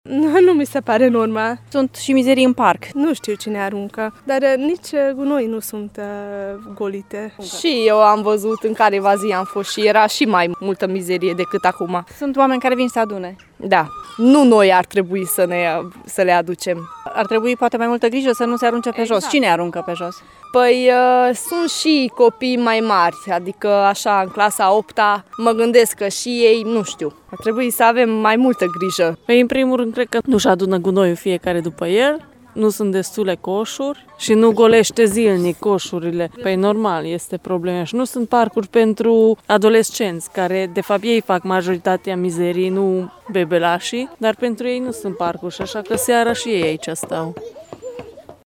Deși nu este normal ca oamenii să adune gunoiul din parcuri, spiritul civic ar trebui mai mult cultivat, cred mămicile din Târgu Mureș: